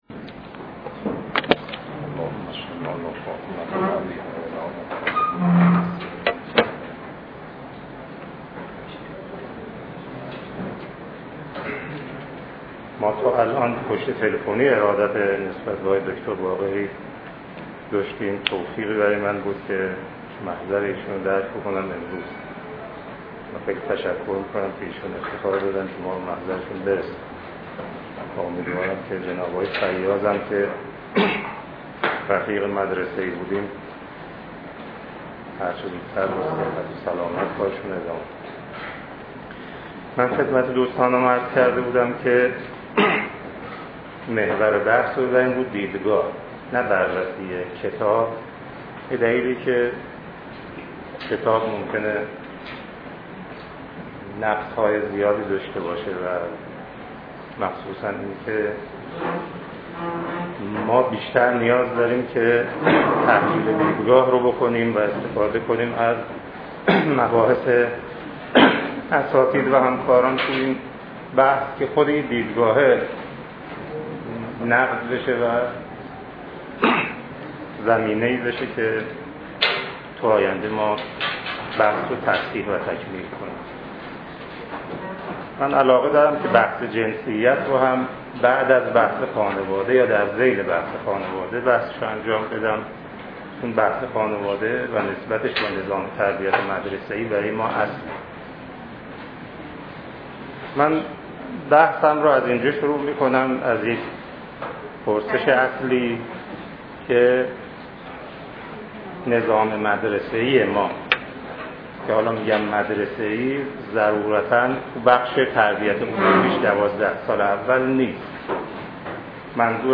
سخنرانی
در دانشکده روانشناسی دانشگاه تهران